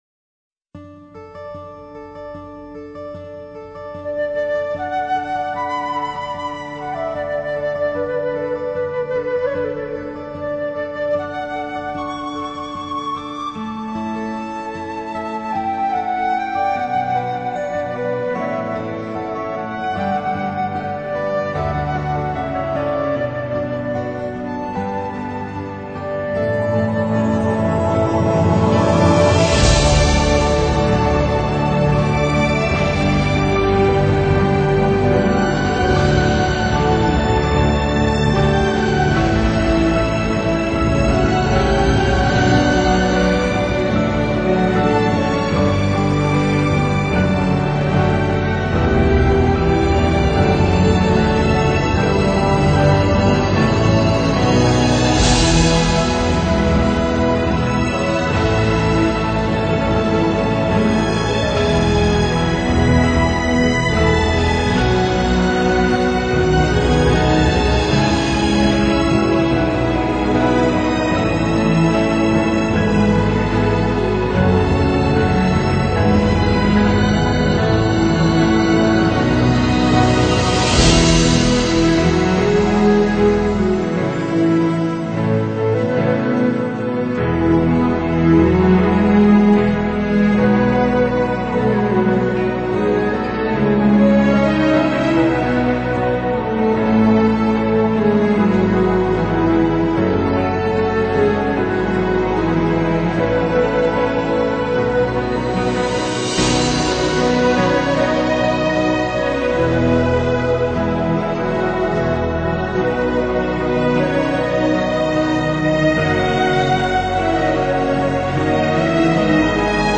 大胆地运用抑扬的旋律，令人向往的轻松口哨声，深情溅落的铙钹 声，以及渐渐激烈的内心挣扎作为渲染